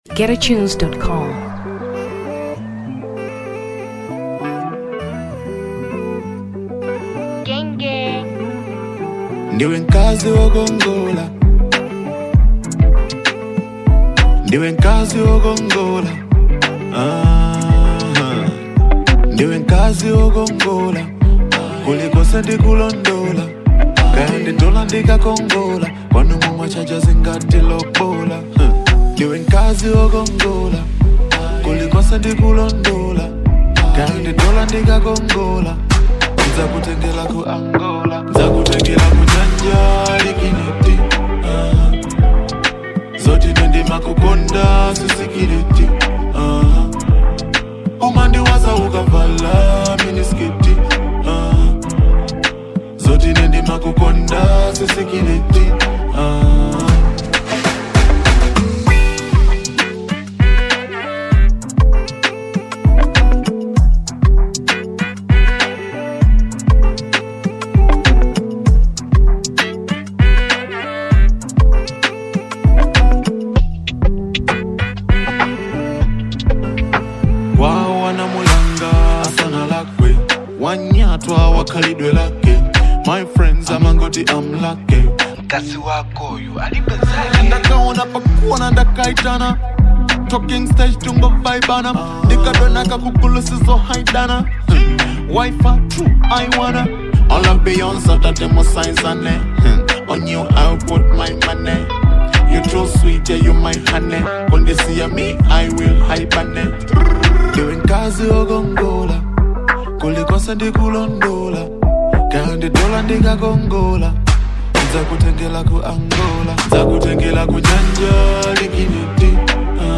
Dancehall Fusion 2023 Malawi